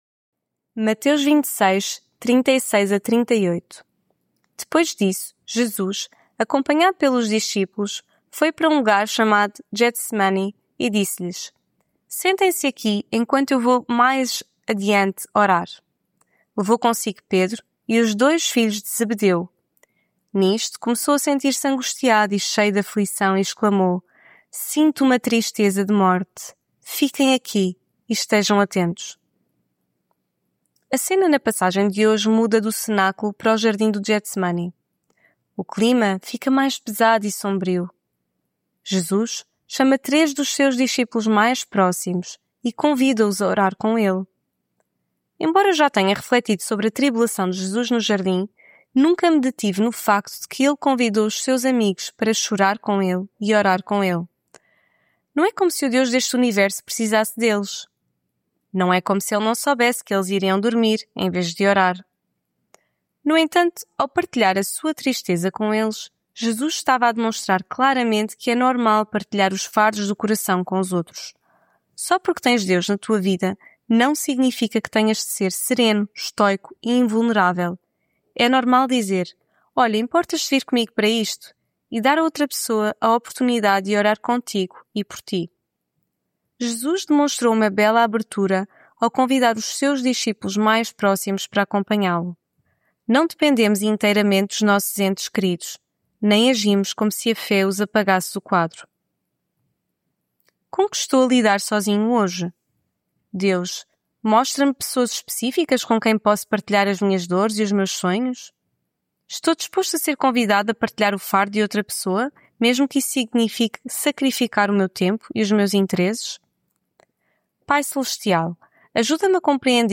Devocional Quaresma